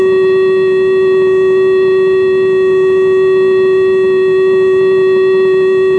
Com sonen les sirenes de risc químic (2025)
Aquest registre conté els sons emesos per les sirenes d’avís a la població en situacions de risc químic.
El senyal de fi d’alerta és un so continu de 30 segons.
so sirenes fi alerta risc químic.mp3